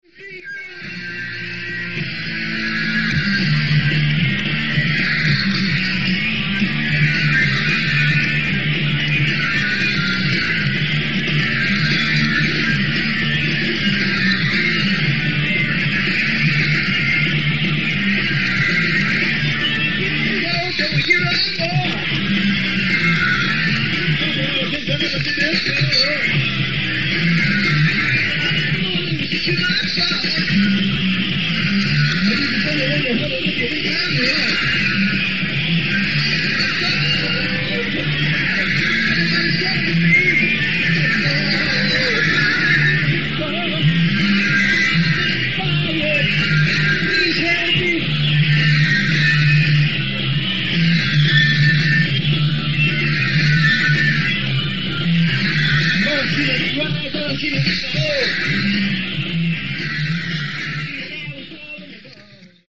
Venue:  Grona Lunden / Open Air Festival
Sound:  Remastered
Source:  Audience